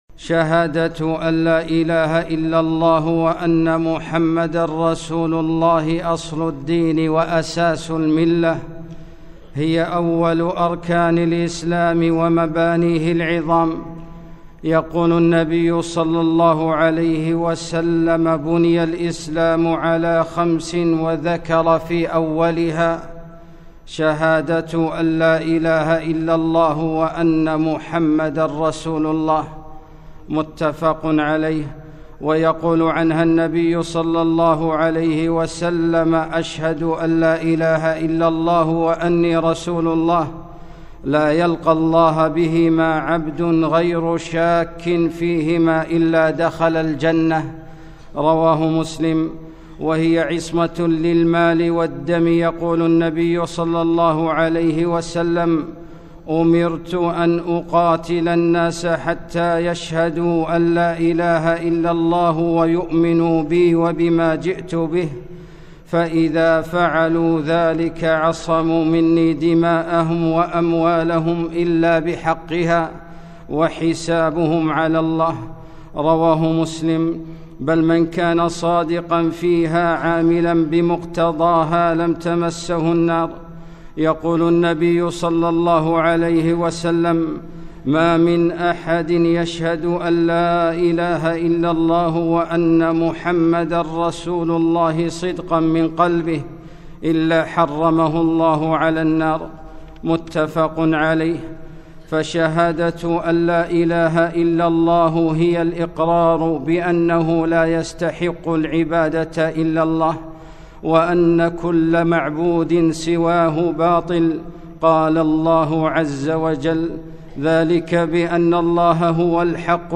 خطبة - ركن الإسلام الأعظم